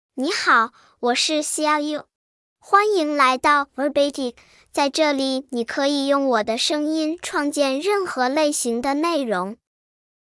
XiaoyouFemale Chinese AI voice
Xiaoyou is a female AI voice for Chinese (Mandarin, Simplified).
Voice: XiaoyouGender: FemaleLanguage: Chinese (Mandarin, Simplified)ID: xiaoyou-zh-cn
Voice sample
Listen to Xiaoyou's female Chinese voice.